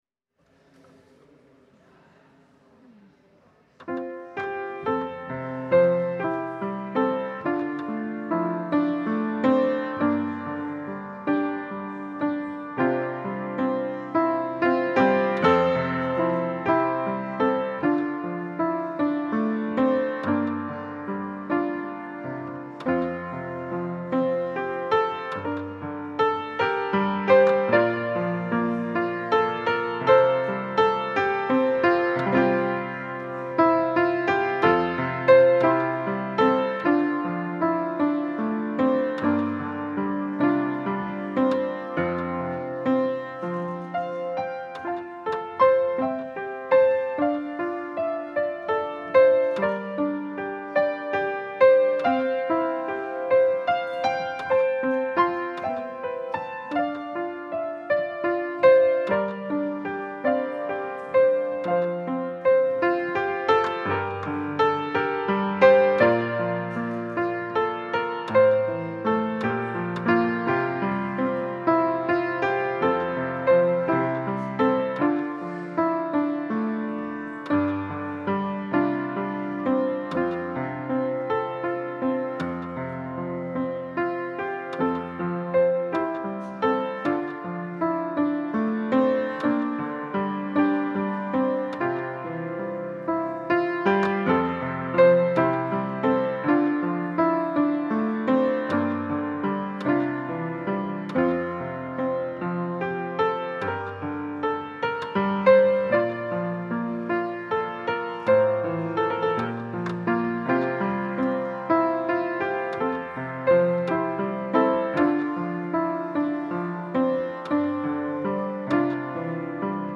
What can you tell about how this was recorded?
Passage: Galatians 5: 1 Service Type: Sunday Service Scriptures and sermon from St. John’s Presbyterian Church on Sunday